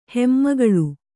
♪ hemmagaḷu